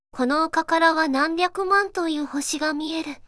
voicevox-voice-corpus
voicevox-voice-corpus / ita-corpus /四国めたん_セクシー /EMOTION100_044.wav